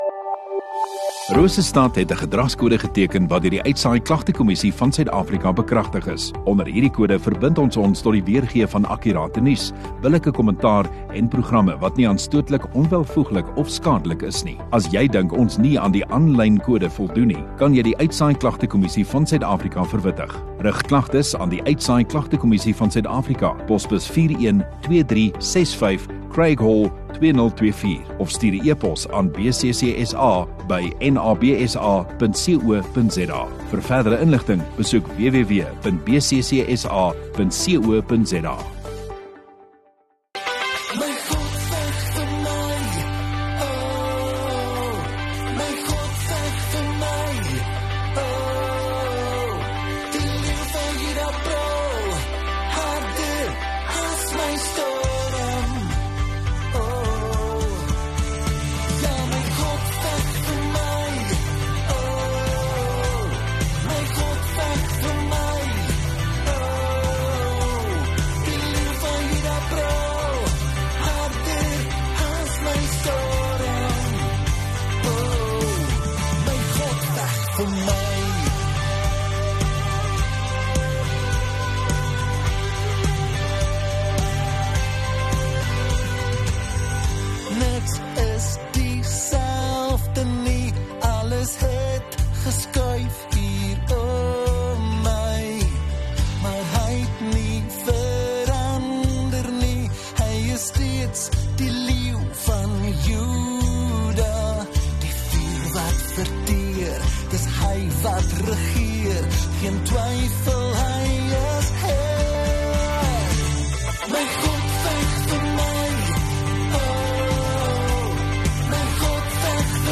2 Apr Donderdag Oggenddiens